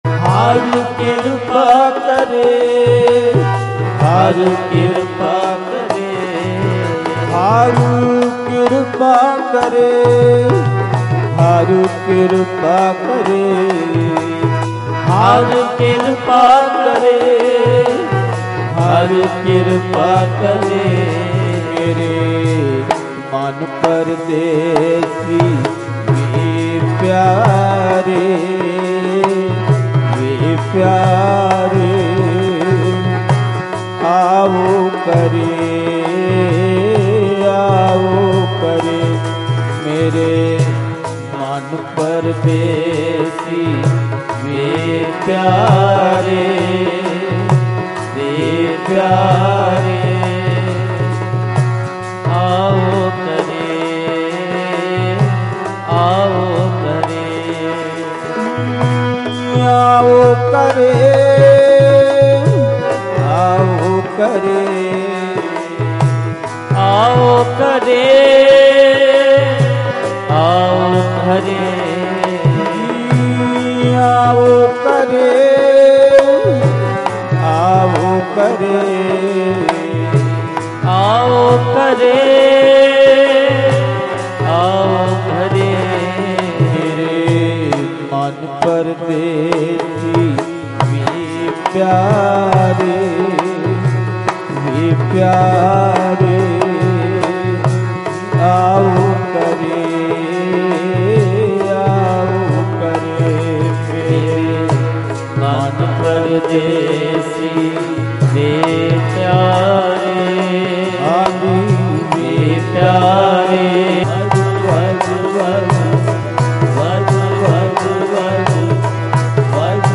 Mp3 Diwan Audio by Bhai Ranjit Singh Ji Dhadrianwale
Live_Gurmat_Samagam_Khan_Ahmedpur_Ambala_4_April_2025_Dhadrianwale.mp3